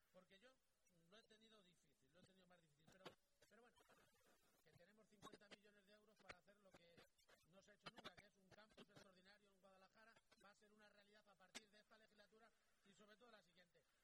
El presidente de Castilla-La Mancha, Emiliano García-Page, habla del proyecto el Campus Universitario de Guadalajara.